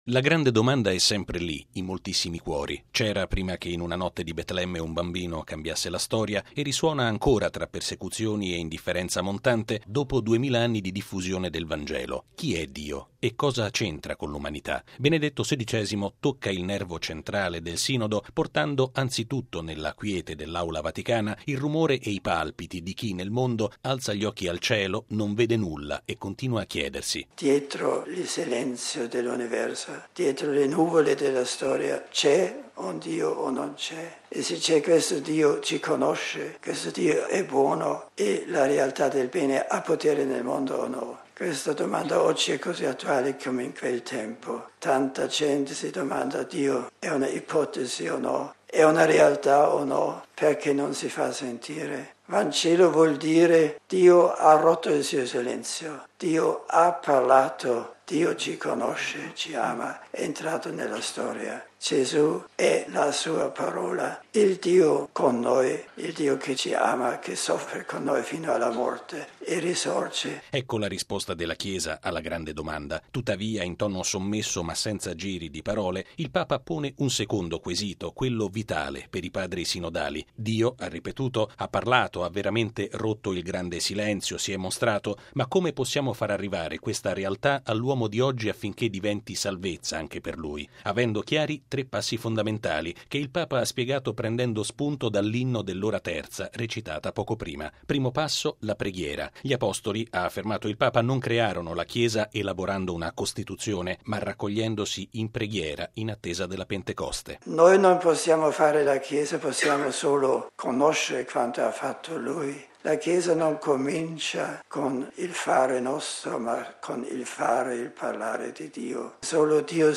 Con questi pensieri, Benedetto XVI ha aperto stamattina i lavori del Sinodo sulla nuova evangelizzazione. Il Papa ha preso la parola dopo la lettura e l’Inno iniziali con una meditazione spontanea e particolarmente intensa.
Tuttavia, in tono sommesso ma senza giri di parole, il Papa pone un secondo quesito, quello vitale per i Padri sinodali: “Dio – ha ripetuto Benedetto XVI – ha parlato, ha veramente rotto il grande silenzio, si è mostrato.